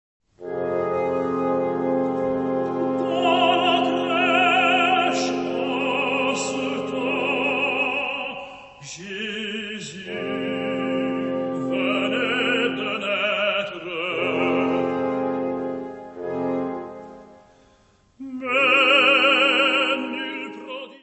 : stereo; 12 cm
Music Category/Genre:  Classical Music